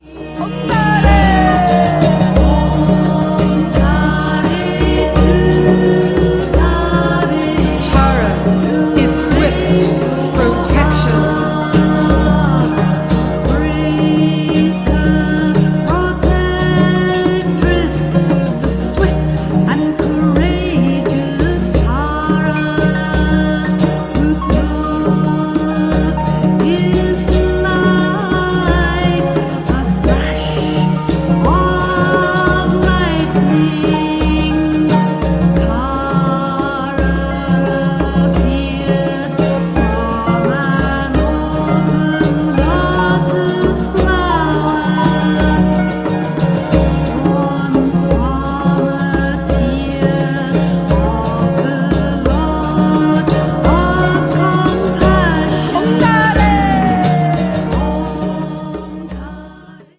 Deeply meditative and nourishing to the soul